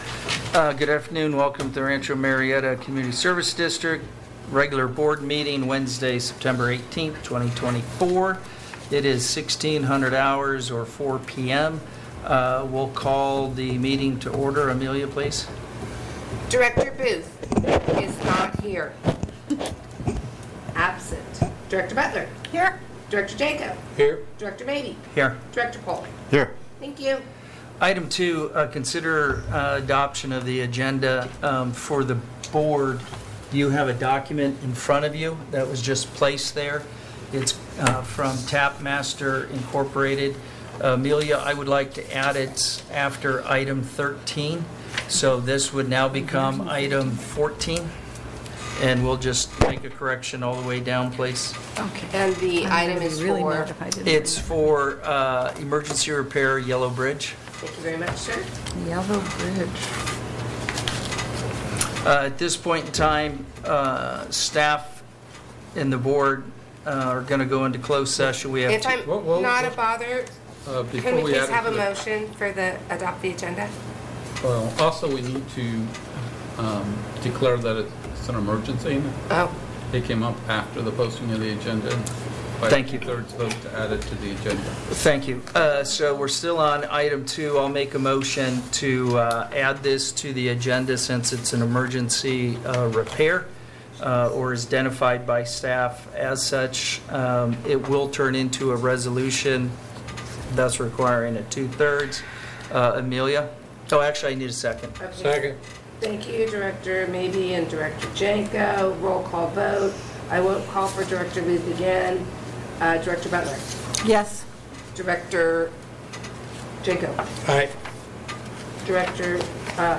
Board of Directors SPECIAL MEETING AT 1:00 P.M.